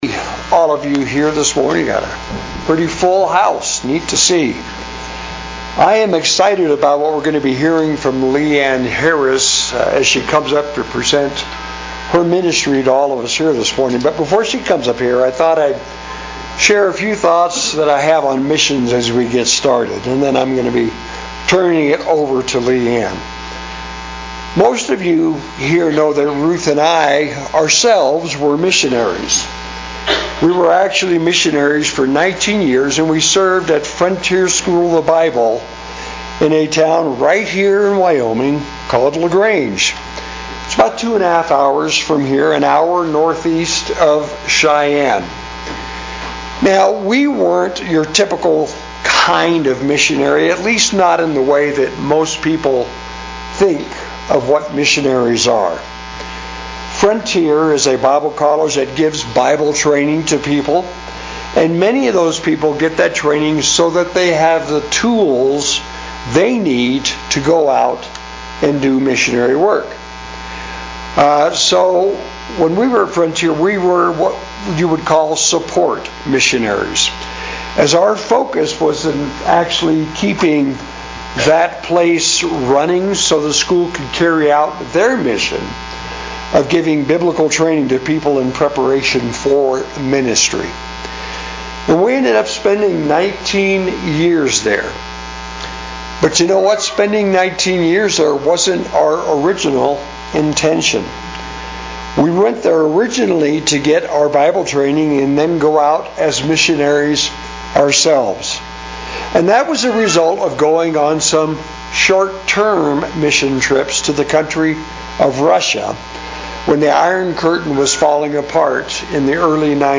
Quest Missionary Speaker